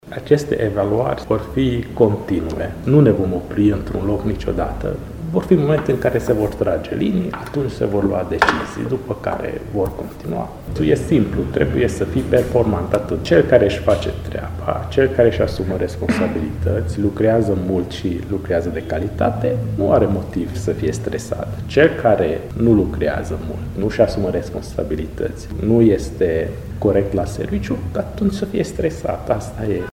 insert-2-primar.mp3